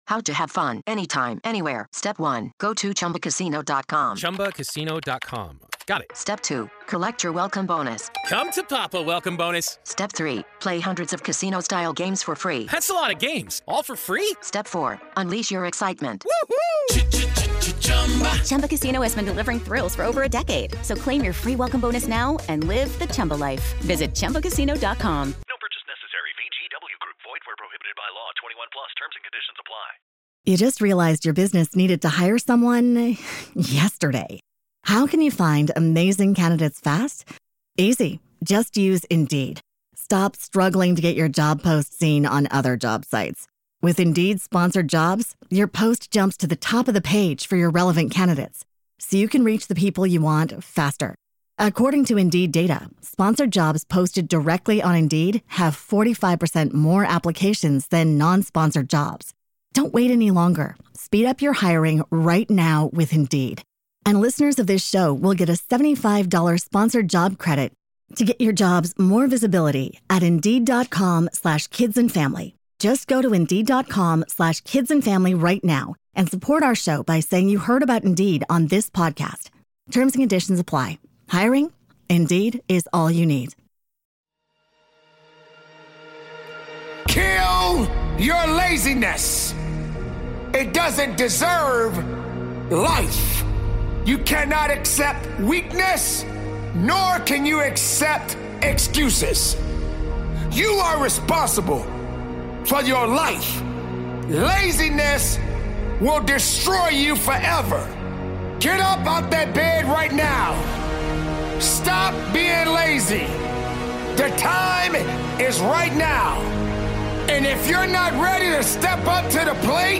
It's time to lock in, stay focused, and discipline yourself. Powerful Motivational Speech